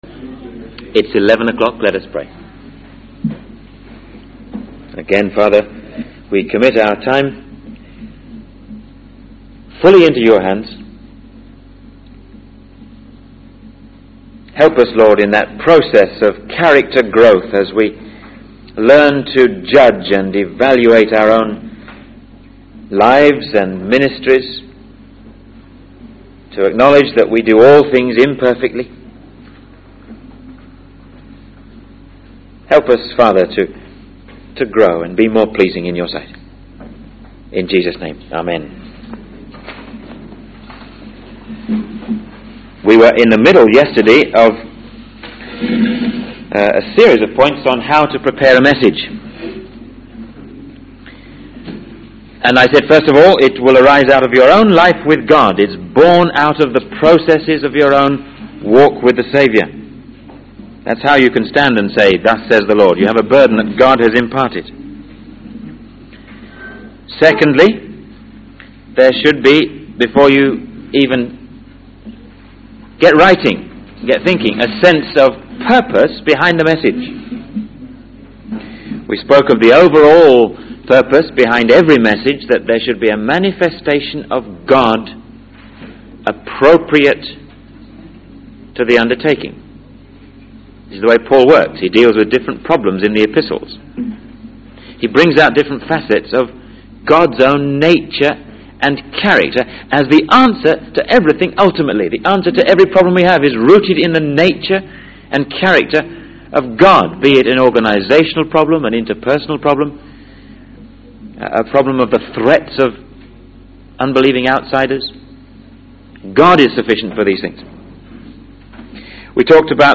In this video, the speaker discusses the importance of crafting a sermon that is both listenable and impactful. He emphasizes the need for effective illustration, using examples from his own experiences to engage the audience.